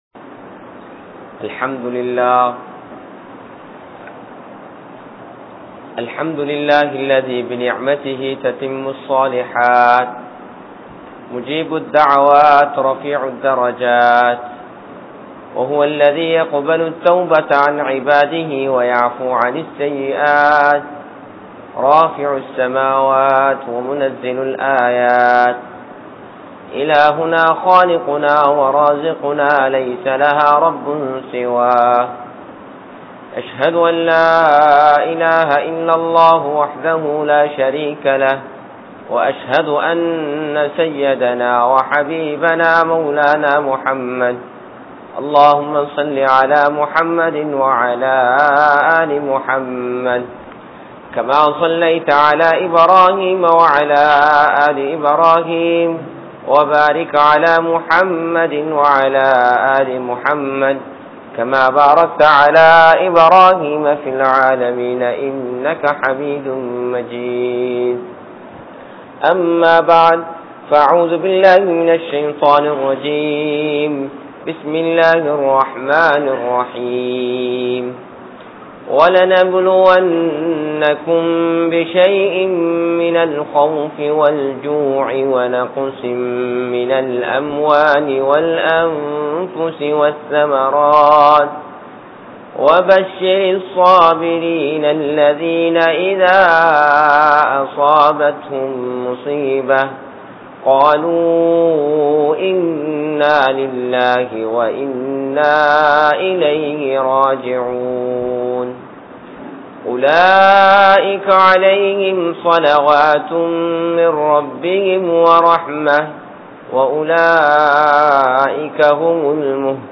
How to Face a current Situation | Audio Bayans | All Ceylon Muslim Youth Community | Addalaichenai